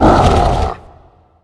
spawners_mobs_balrog_hit.ogg